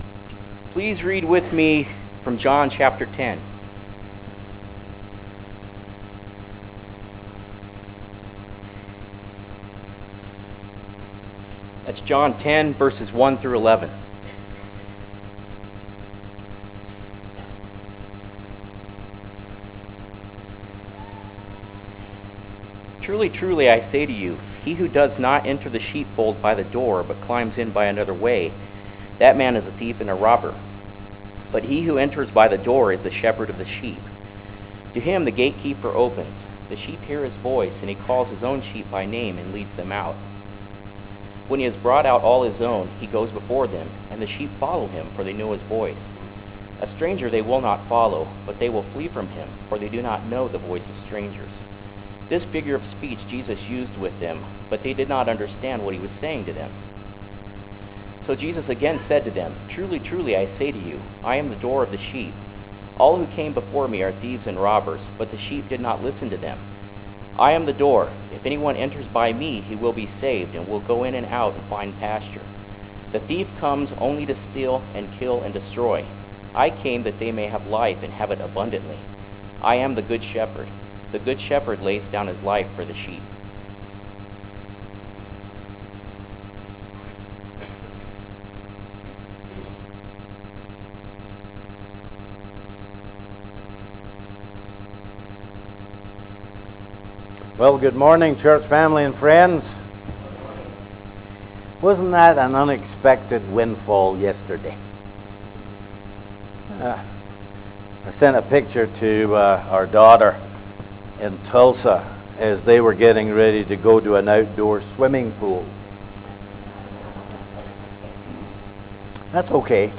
from → Classes / Sermons / Readings, Sermon